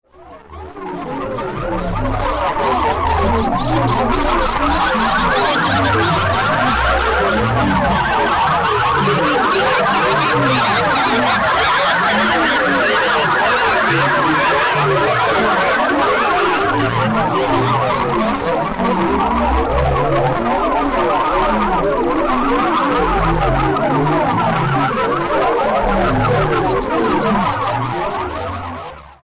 Ultimately we ended up with a three part piece, a sonic journey that took us from the city (city sounds) to the country (animal and insect sounds) and finally to outer space (warped city sounds and sound effects).